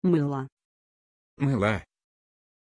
Pronunția numelui Mylah
pronunciation-mylah-ru.mp3